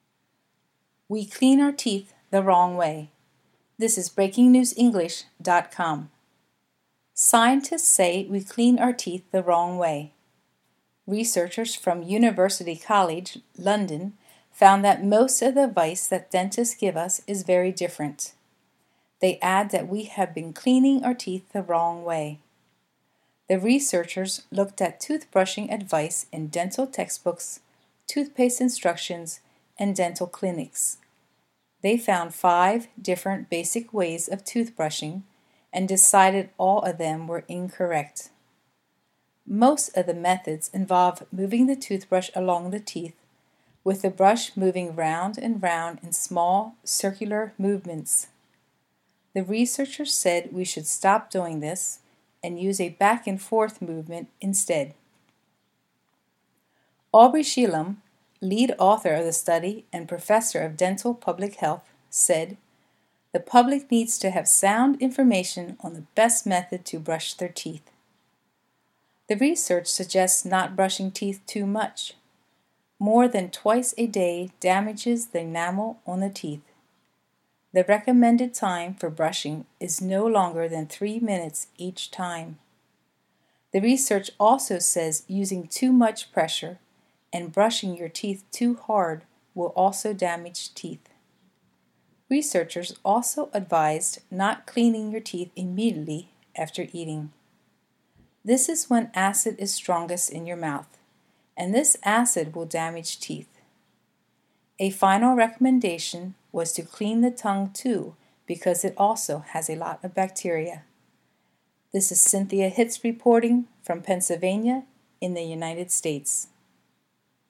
British speaker (male)